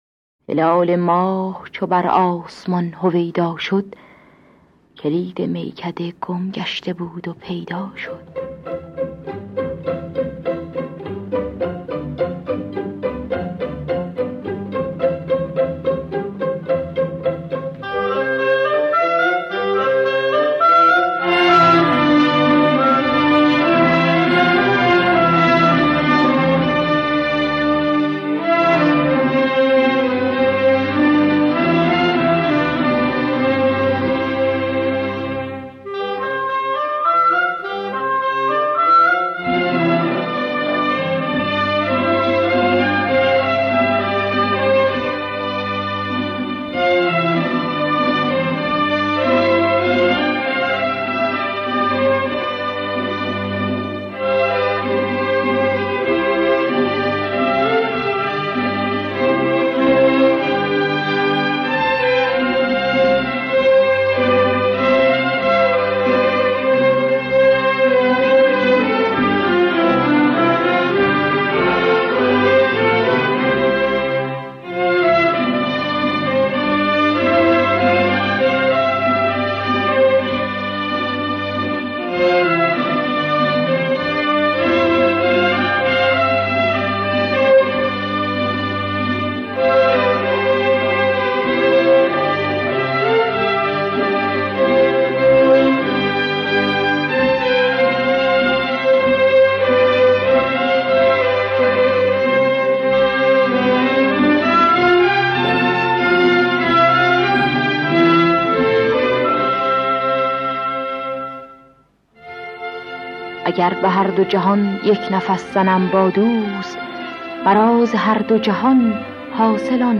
در دستگاه ماهور